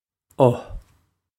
o uh
This is an approximate phonetic pronunciation of the phrase.